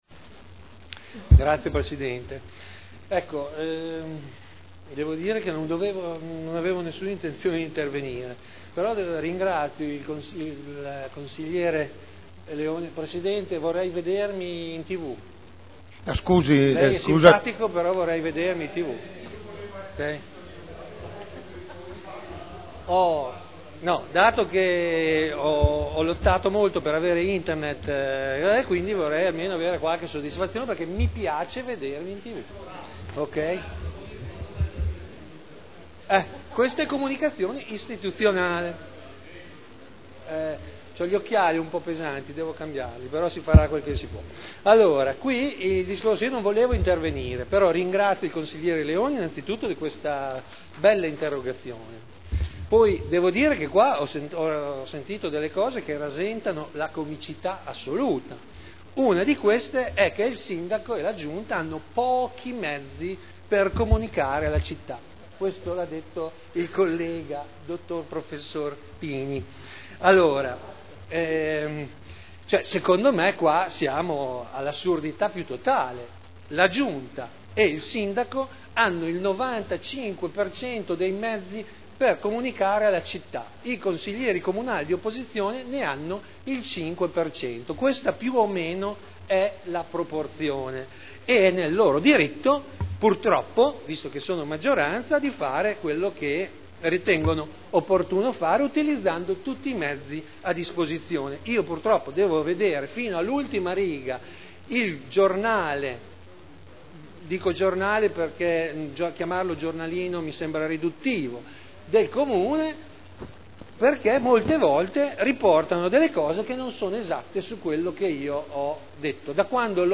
Seduta del 3/02/2011.